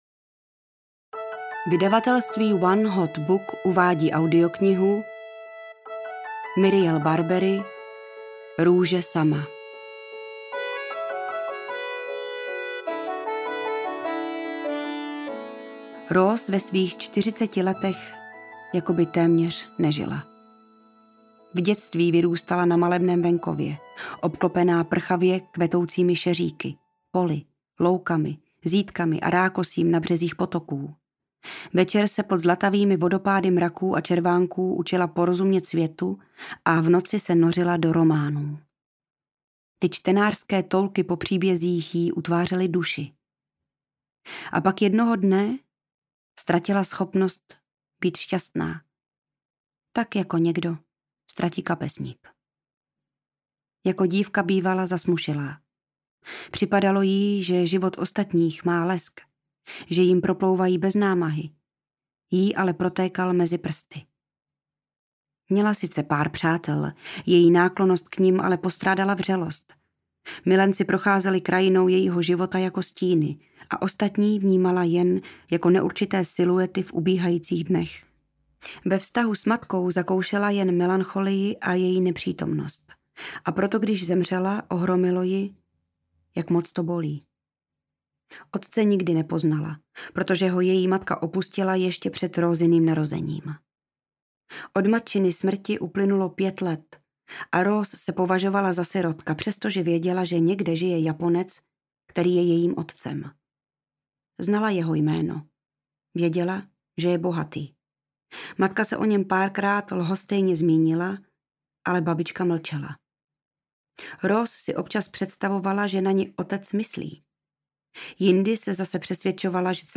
Růže sama audiokniha
Ukázka z knihy